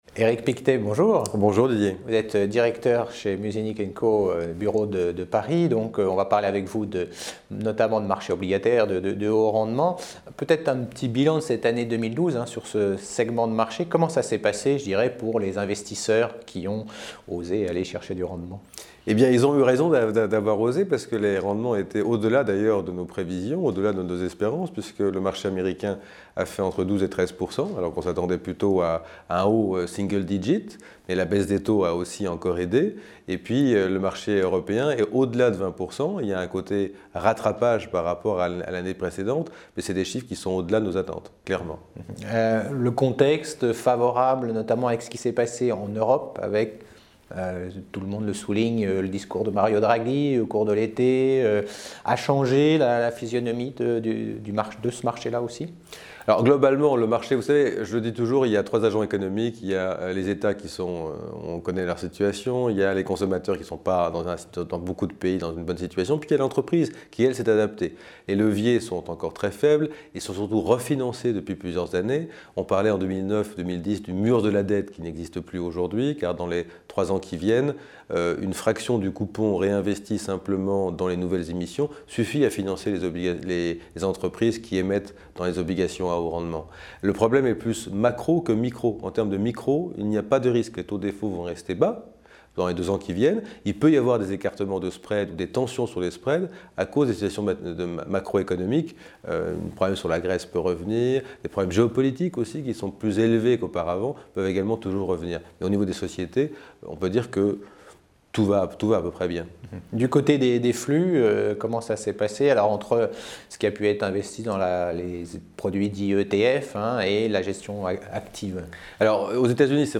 High Yield : Interview